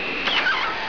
File: "mecanic squeaking" (cigolio meccanico)
Type: Sound Effect